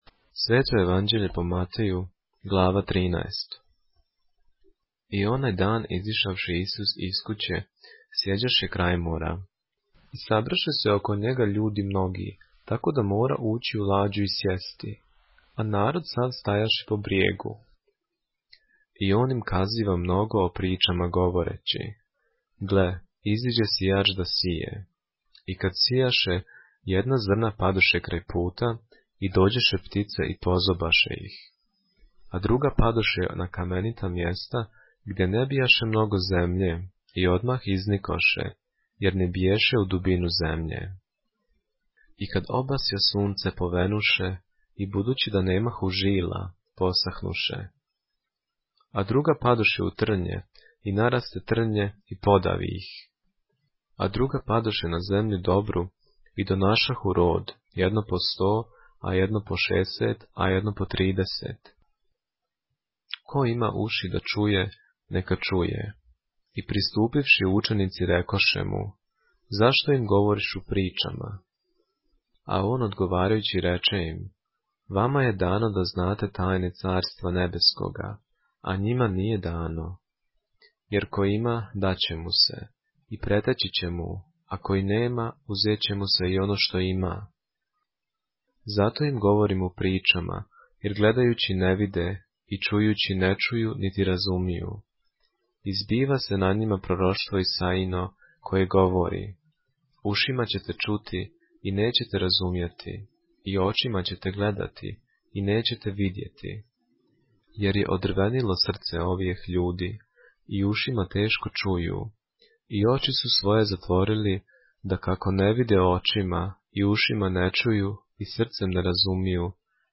поглавље српске Библије - са аудио нарације - Matthew, chapter 13 of the Holy Bible in the Serbian language